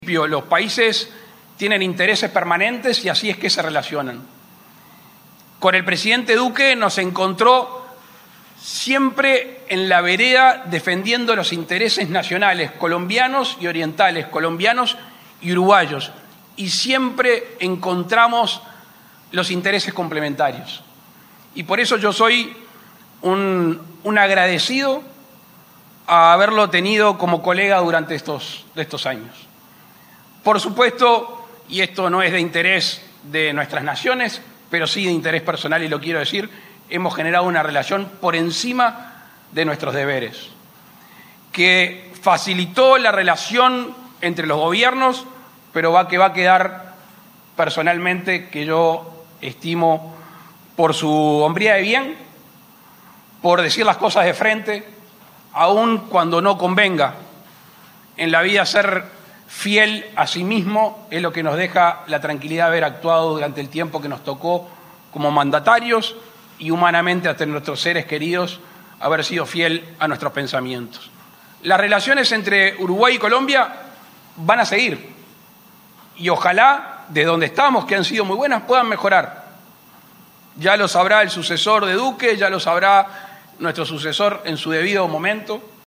El presidente de la República, Luis Lacalle Pou, sostuvo en su discurso que las relaciones con Colombia «van a seguir» independiente de quien se encuentre en el gobierno.